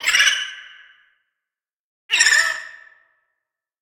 Sfx_creature_babypenguin_call_01.ogg